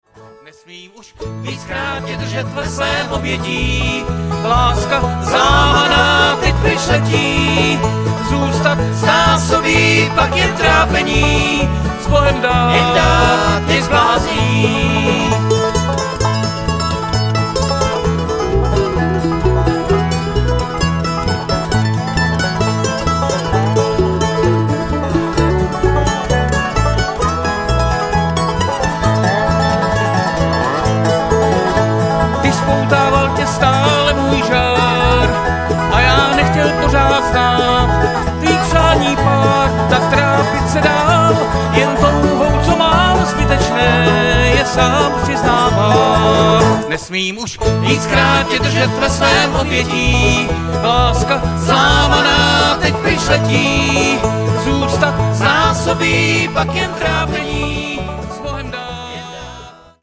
Guitar
Banjo
Mandolin
Dobro
Electric Bass